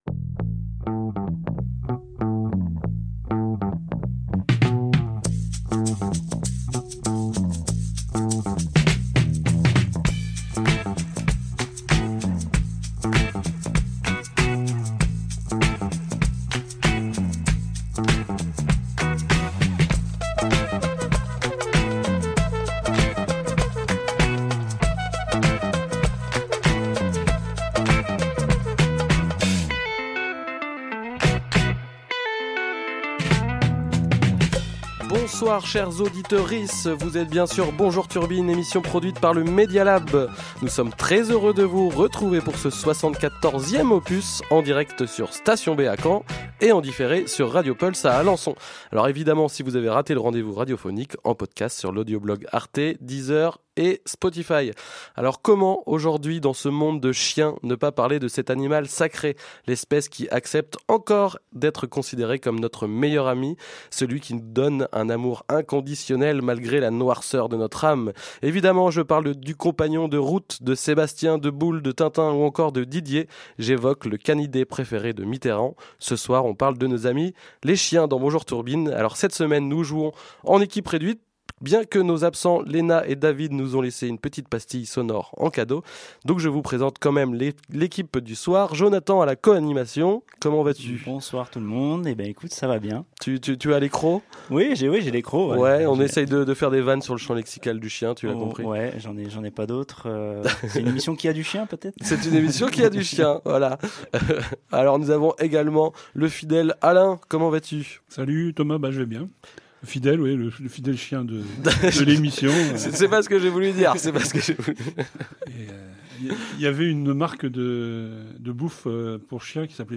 Radio Pulse 90.0FM à Alençon